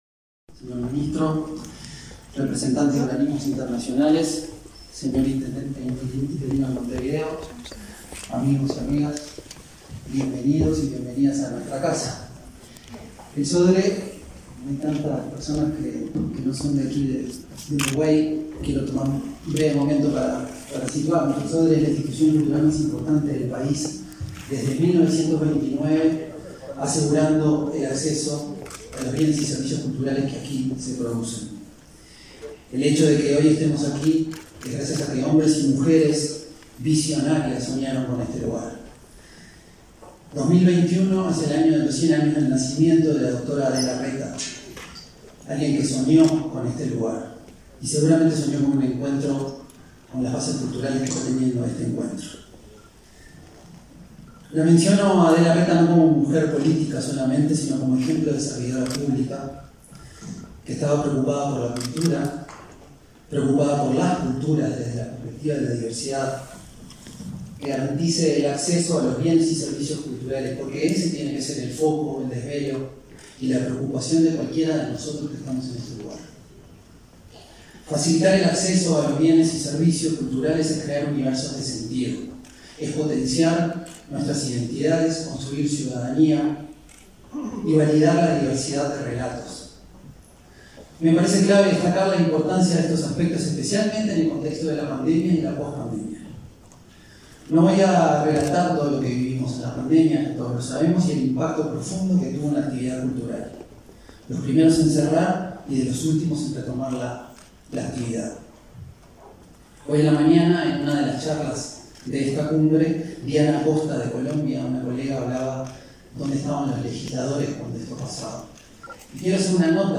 Acto de celebración del 15.º aniversario de la Carta Cultural Iberoamericana
Acto de celebración del 15.º aniversario de la Carta Cultural Iberoamericana 04/11/2021 Compartir Facebook X Copiar enlace WhatsApp LinkedIn El presidente de la República, Luis Lacalle Pou, encabezó celebración del 15.º aniversario de la Carta Cultural Iberoamericana, realizada este 4 de noviembre. Participaron, entre otras autoridades, el secretario general de la Organización de Estados Iberoamericanos, Mariano Jabonero, y el presidente del Sodre, Martín Inthamoussú.